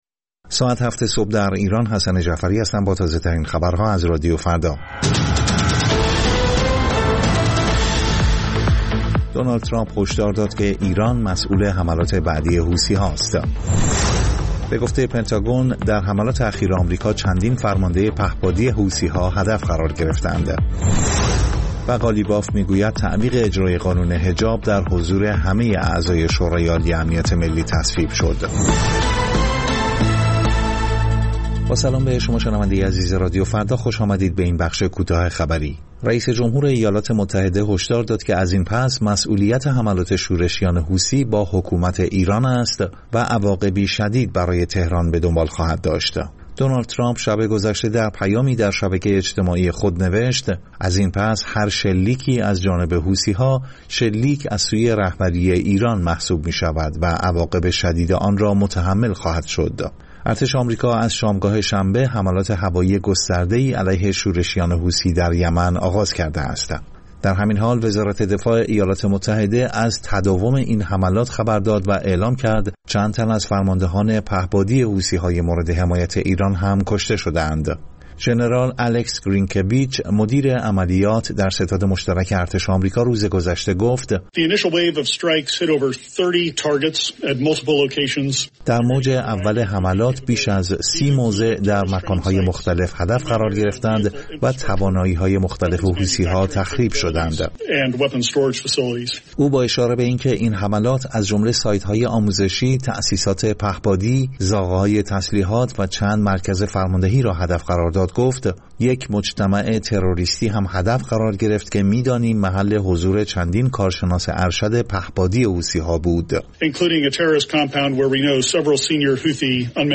سرخط خبرها ۷:۰۰